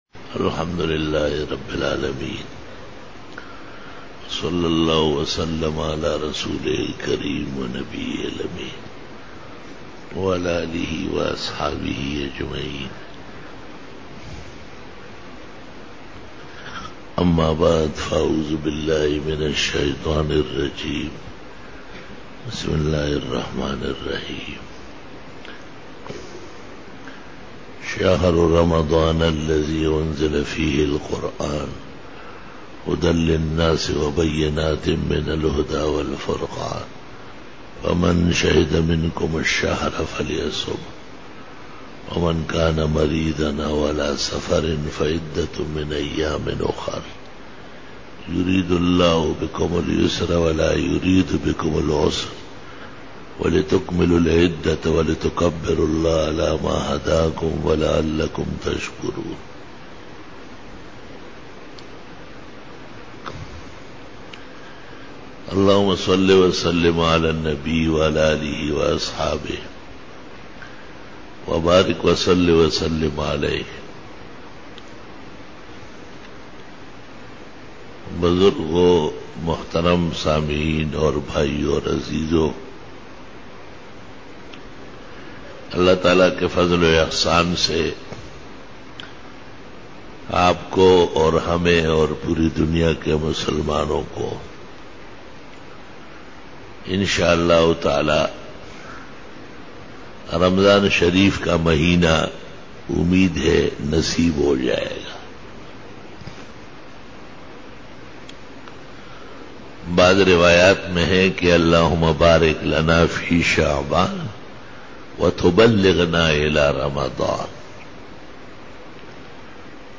26_Bayan e Juma tul Mubarak 5-july- 2013
بیان جمعۃ المبارک 5 جولائی 2013